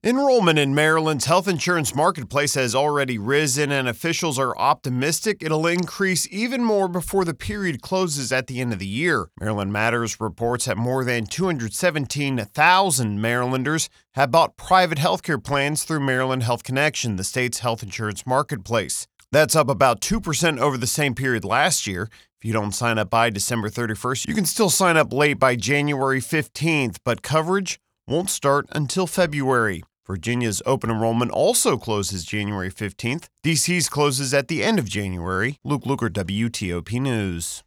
Live Radio